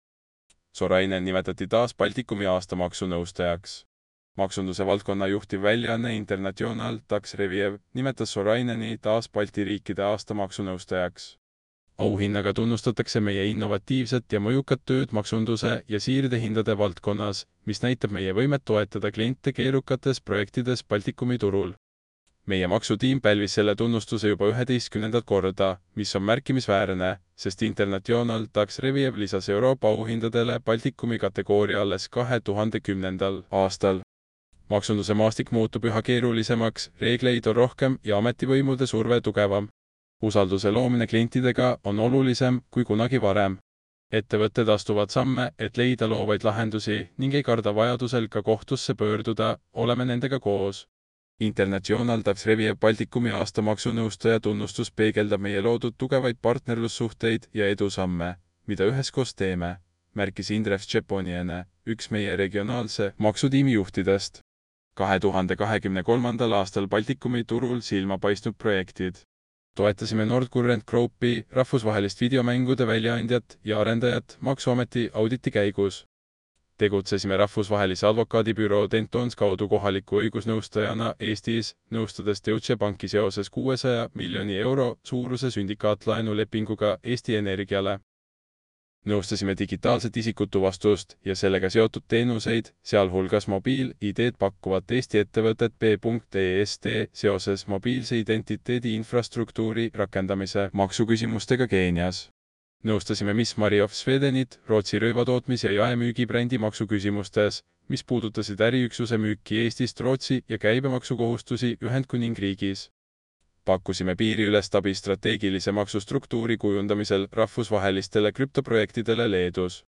Kui silmad puhkust vajavad, anna artikkel üle kõnerobotile – vajuta ja kuula!